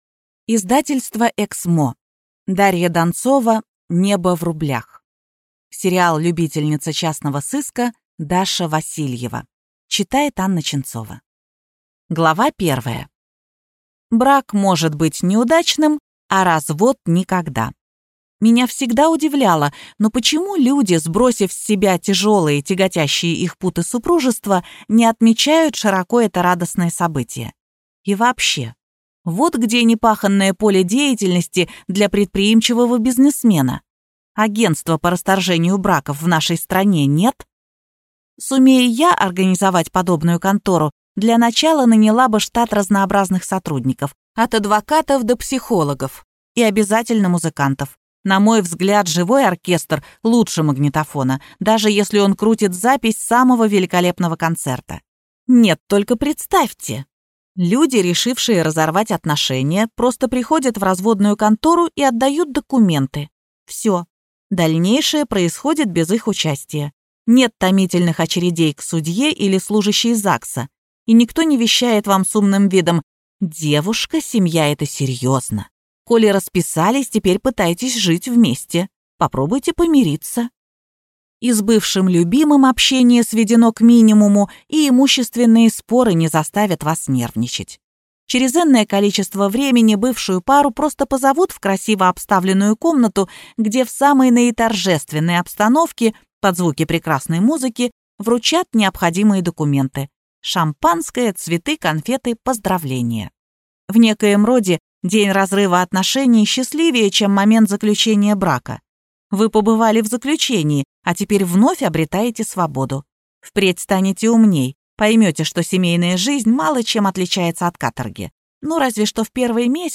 Аудиокнига Небо в рублях | Библиотека аудиокниг